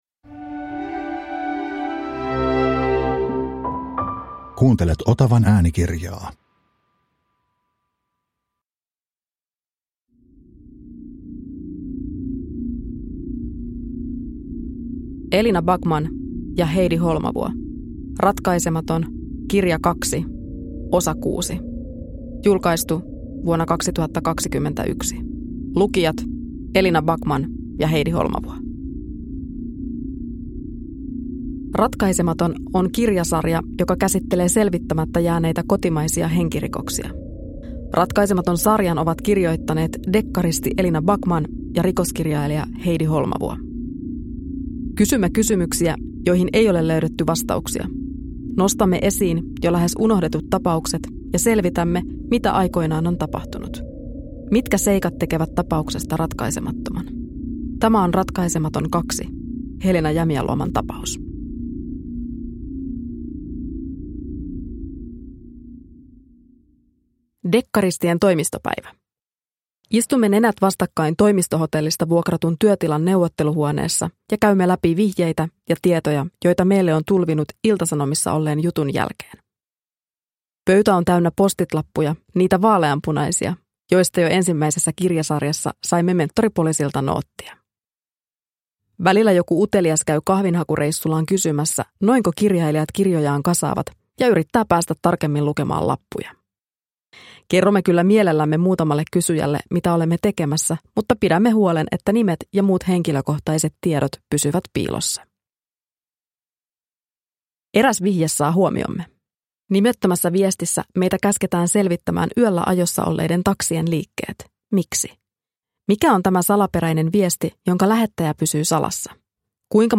Ratkaisematon 6 (ljudbok) av Heidi Holmavuo